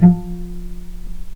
healing-soundscapes/Sound Banks/HSS_OP_Pack/Strings/cello/pizz/vc_pz-F3-pp.AIF at 61d9fc336c23f962a4879a825ef13e8dd23a4d25
vc_pz-F3-pp.AIF